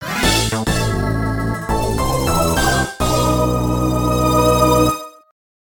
Intro jingle